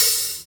HAT ROOM H0B.wav